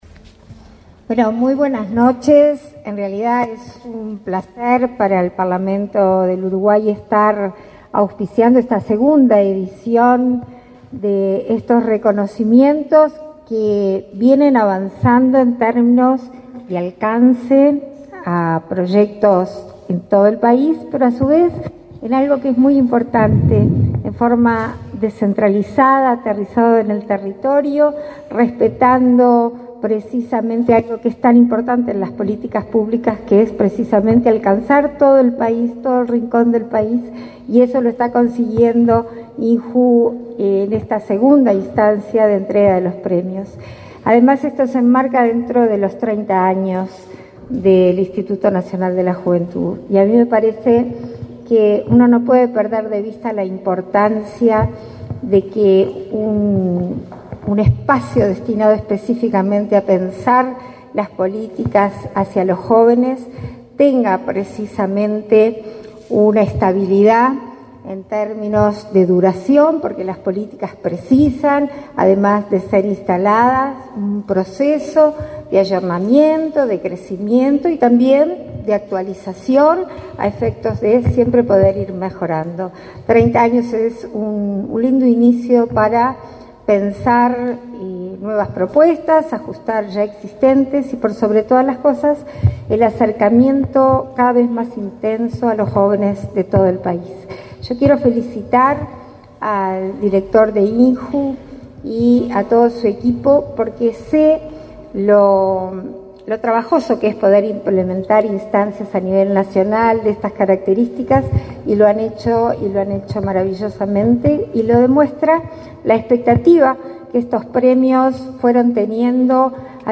Conferencia de prensa por premiación en el INJU
Con la participación de la vicepresidenta de la República, Beatriz Argimón; el ministro de Desarrollo Social, Martín Lema, y el director de Instituto